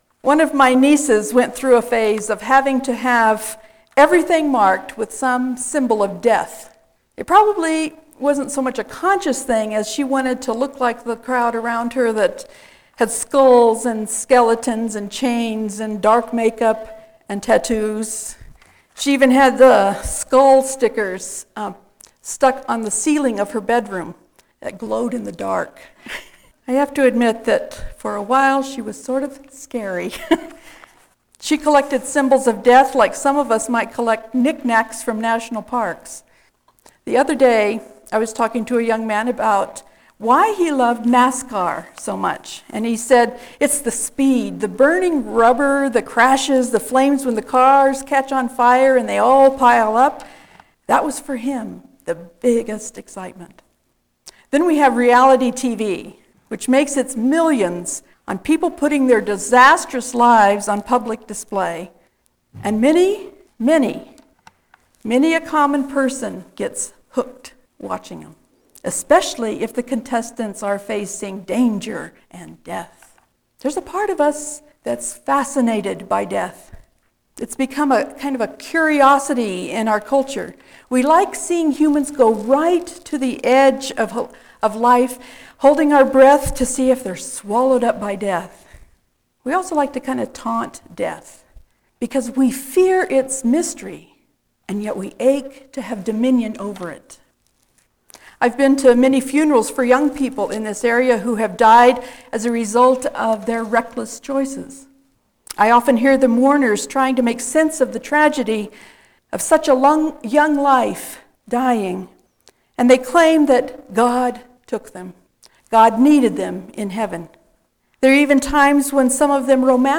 WRPC Sermons: 2013